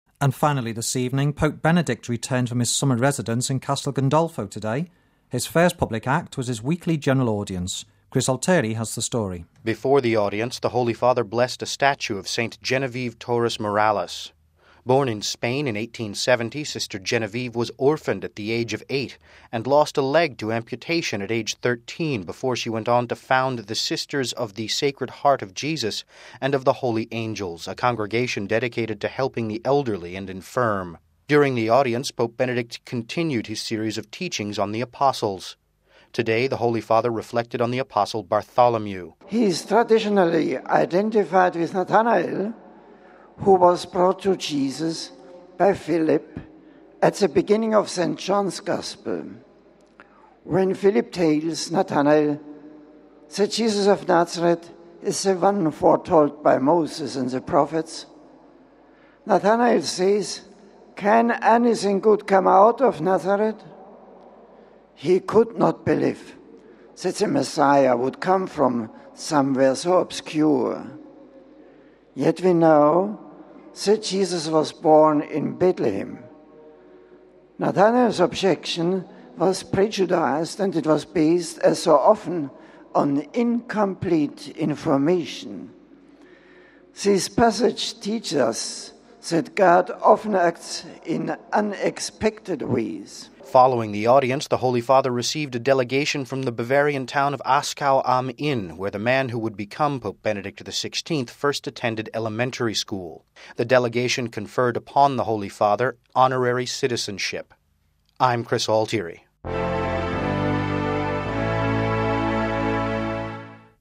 Home Archivio 2006-10-05 10:07:09 Weekly General Audience (4 Oct 06 - RV) Pope Benedict XVI continued his catechesis on the Apostles during his weekly general audience. We have this report...